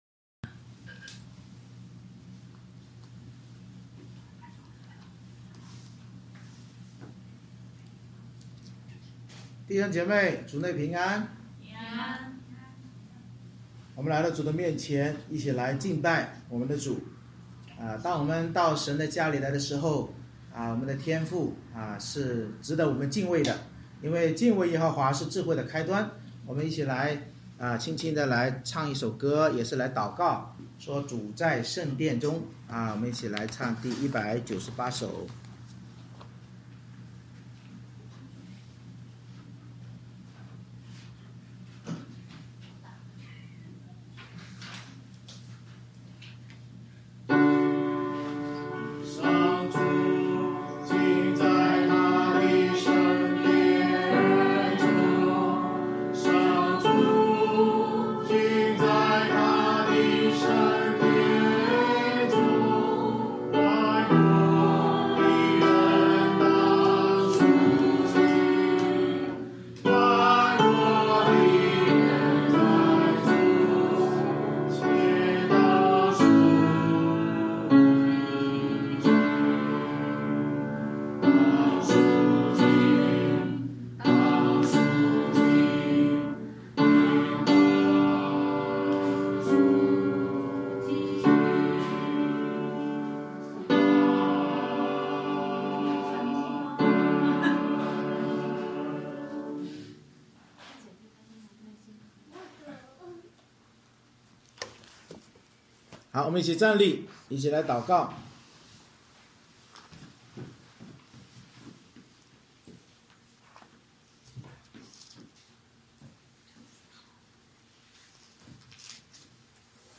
2022年02月06日诗歌敬拜和讲道《受洗的意义》